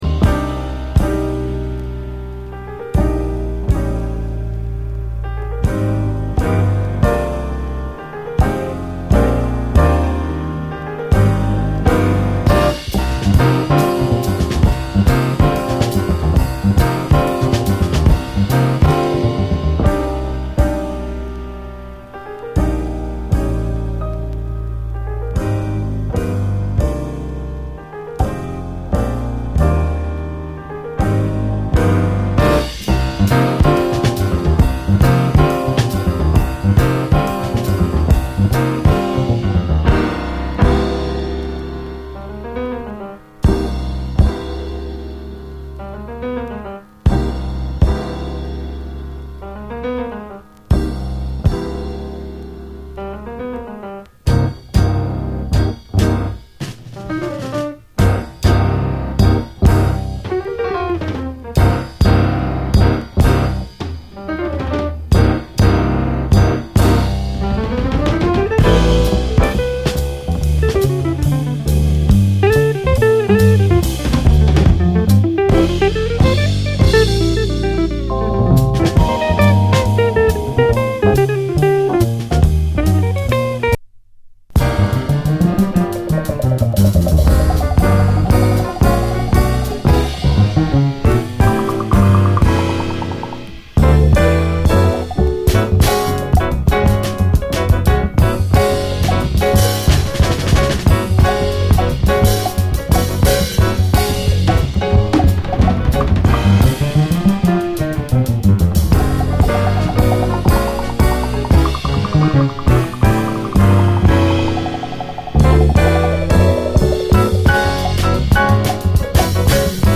JAZZ FUNK / SOUL JAZZ, JAZZ